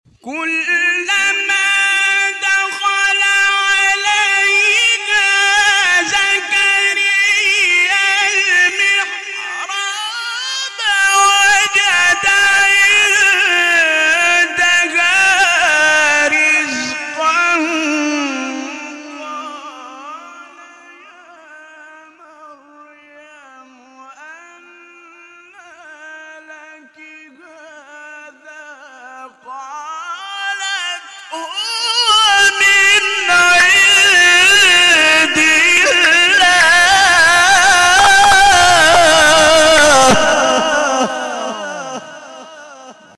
شبکه اجتماعی: فرازهای صوتی از تلاوت قاریان ممتاز کشور را می‌شنوید.